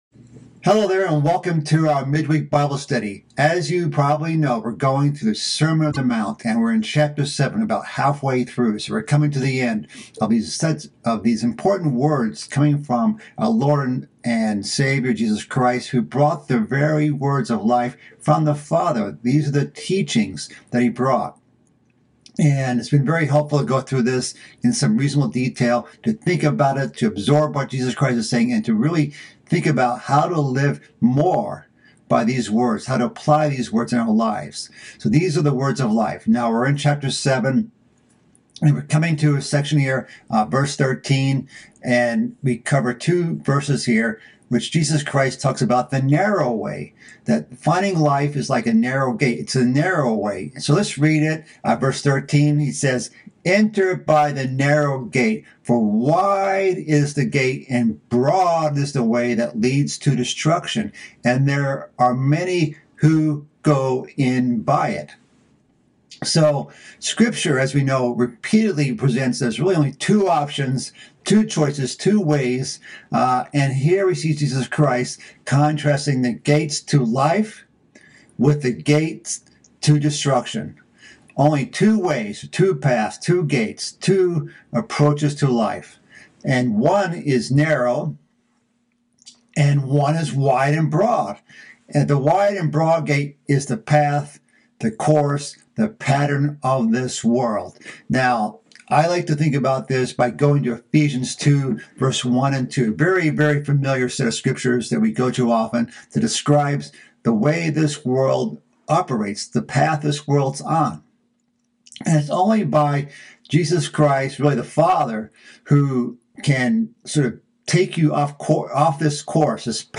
This is part of a mid-week Bible study series about the sermon on the mount. This section covers the narrow way God calls us to.